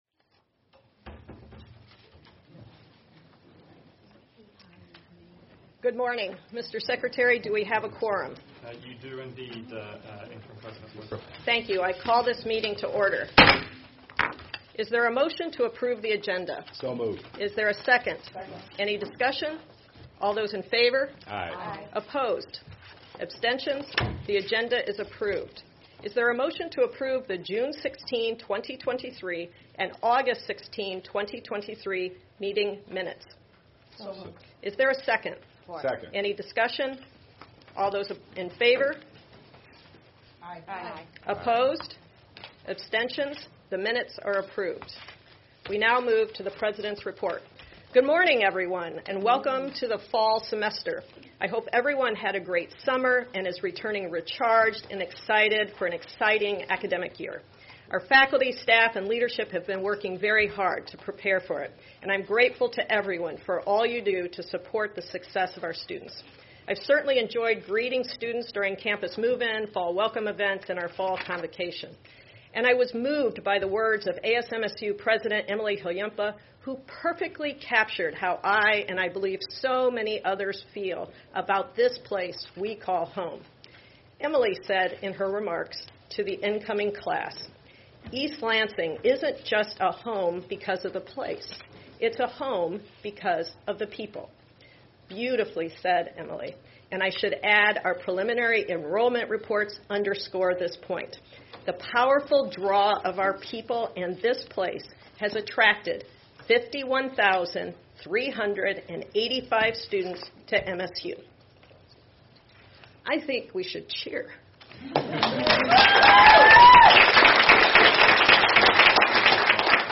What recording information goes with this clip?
Where: Board Room, 401 Hannah Administration Building